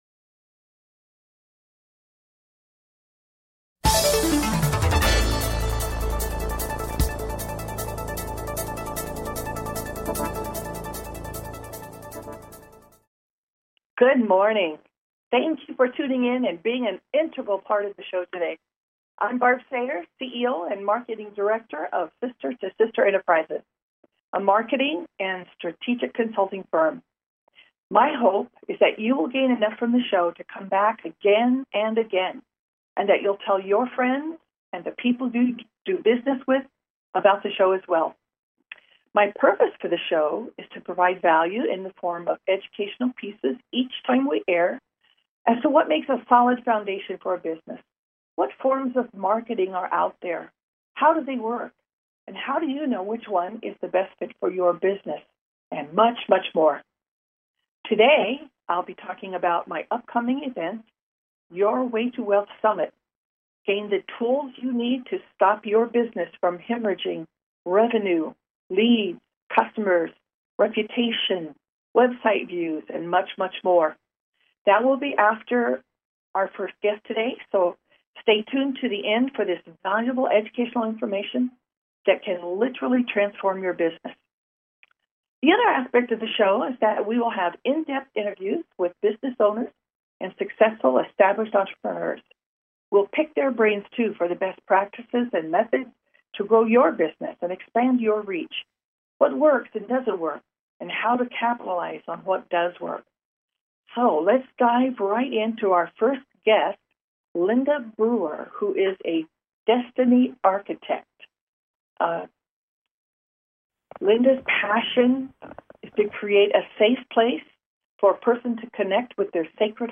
Call-ins encouraged!